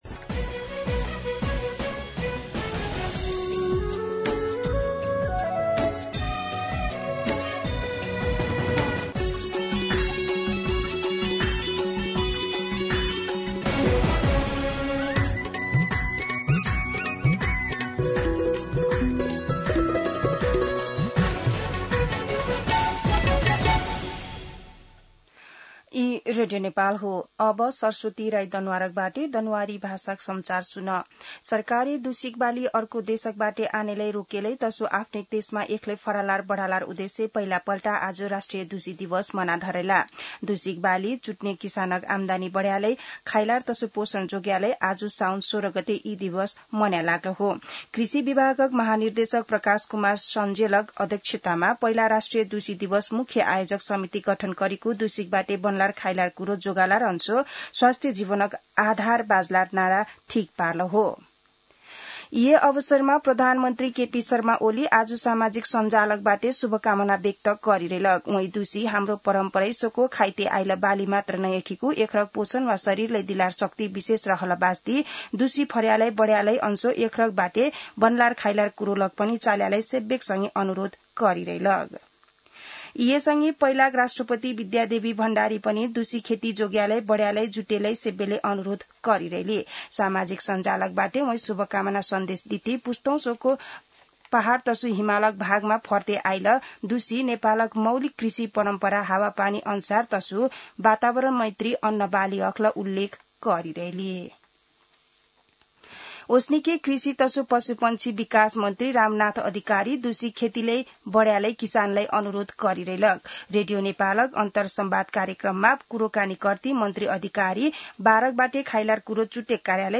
दनुवार भाषामा समाचार : १६ साउन , २०८२
Danuwar-News.mp3